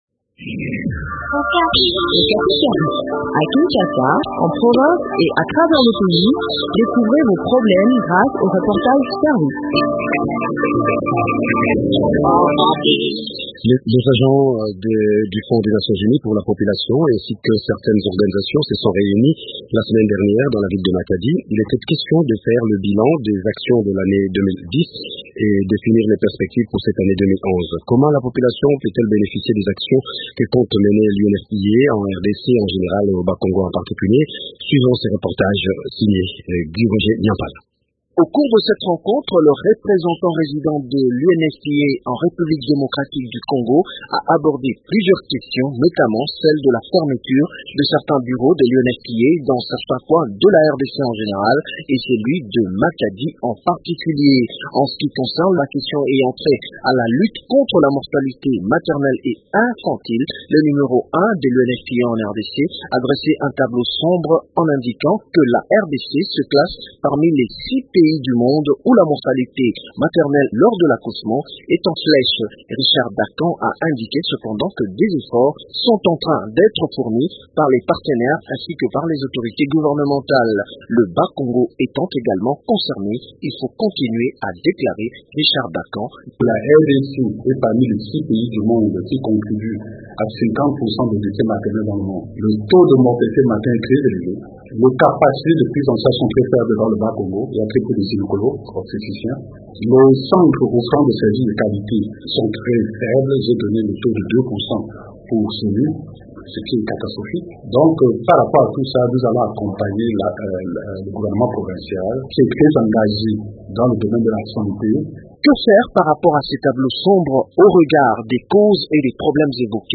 Comment la population peut-elle bénéficier des actions que compte mener l’UNFPA dans le Bas-Congo au cours de cette année? Le point du sujet dans cet entretien